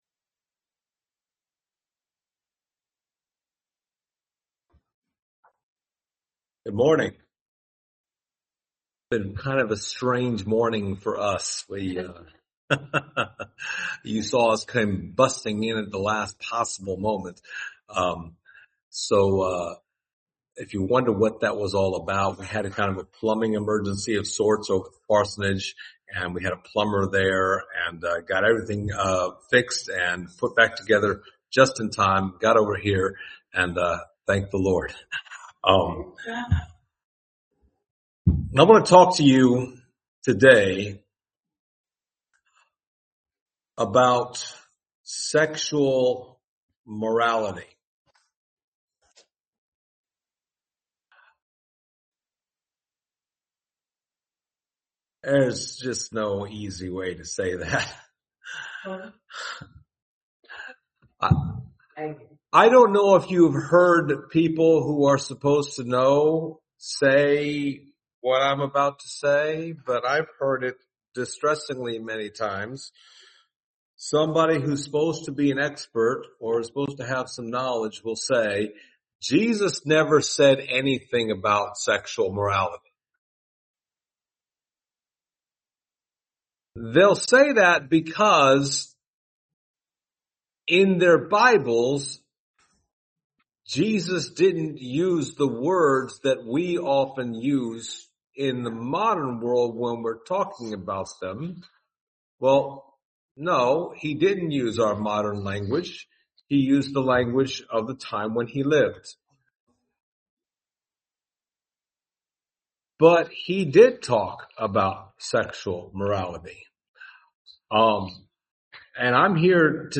Passage: 1 Thessalonians 4:1-3 Service Type: Sunday Morning